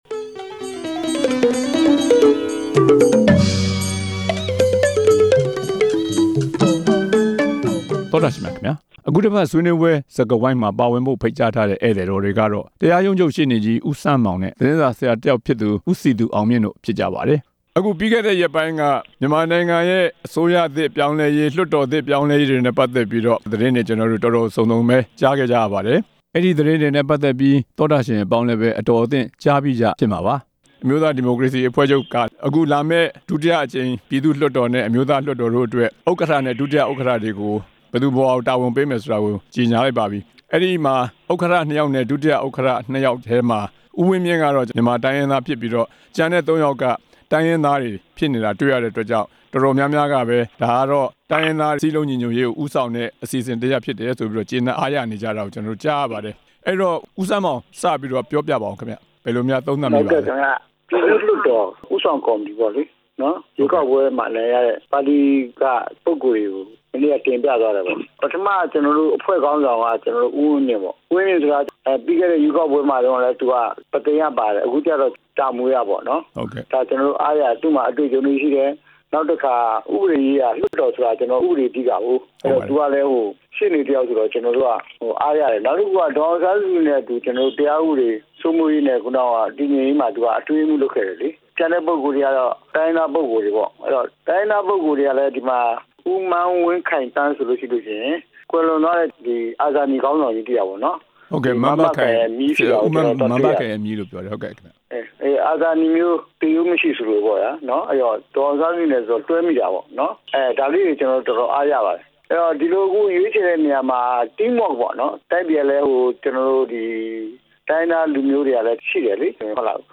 အနိုင်ရ NLD ပါတီရဲ့ လုပ်ဆောင်ချက်များ ဆွေးနွေးချက်